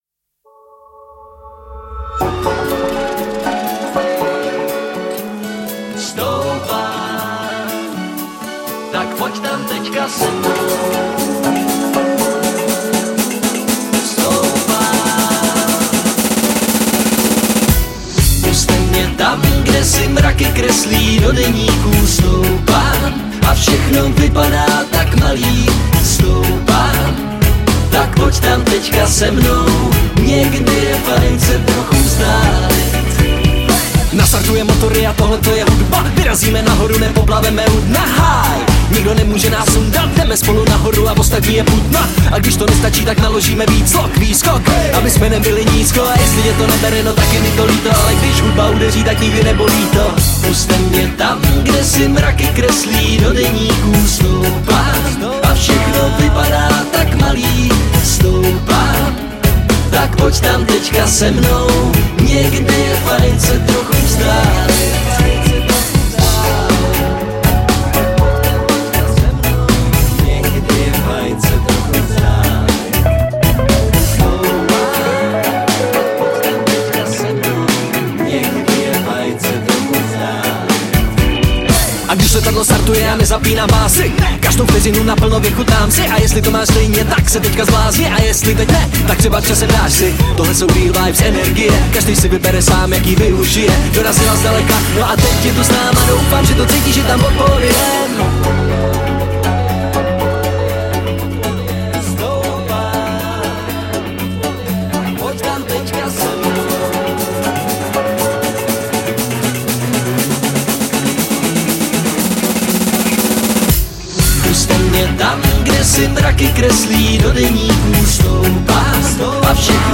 Žánr: Ska/Funk/Reggae